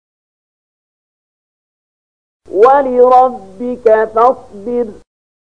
074007 Surat Al-Muddatstsir ayat 7 bacaan murattal ayat oleh Syaikh Mahmud Khalilil Hushariy: